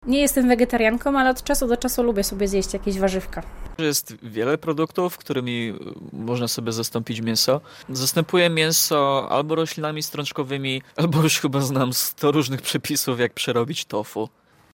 Zapytaliśmy zielonogórzan co sądzą o diecie roślinnej: